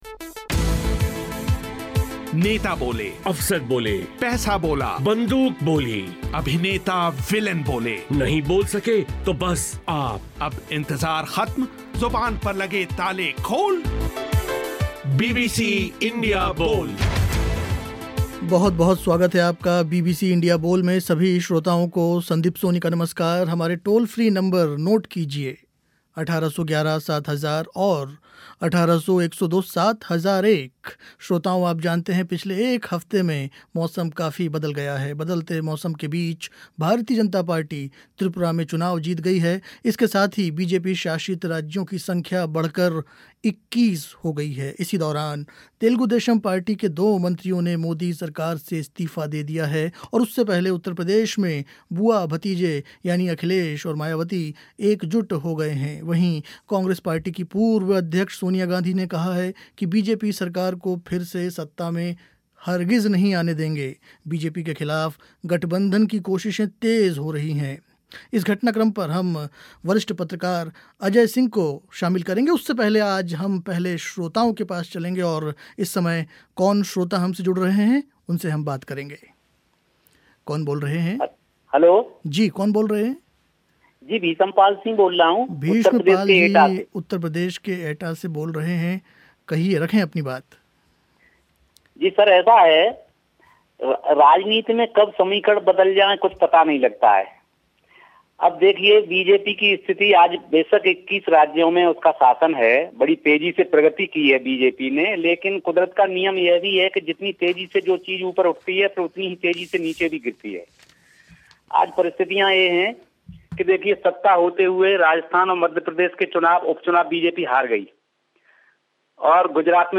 बीबीसी इंडिया बोल में आज चर्चा हुई इसी विषय पर.